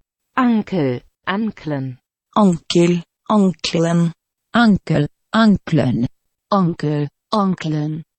File File history File usage Samska_ankel_anklen.ogg (file size: 81 KB, MIME type: application/ogg ) Prono guide for Samska ANKEL File history Click on a date/time to view the file as it appeared at that time.